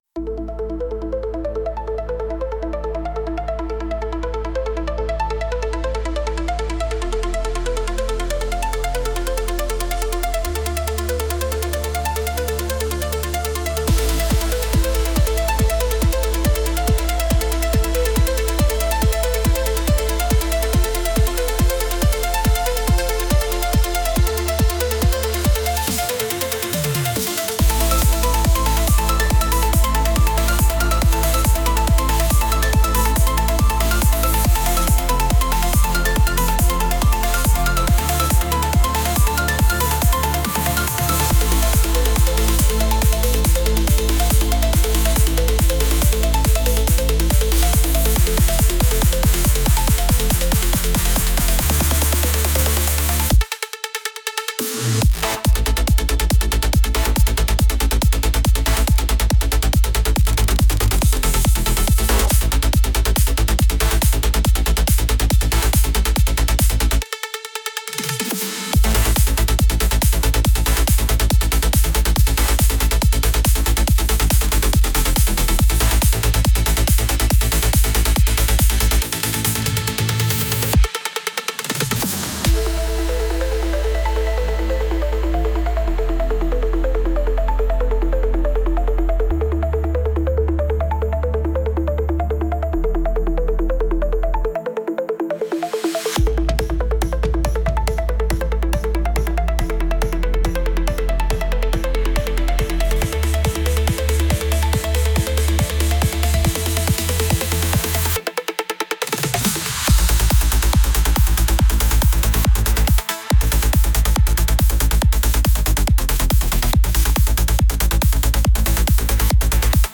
Instrumental, Dance, Techno, House | 04.04.2025 17:24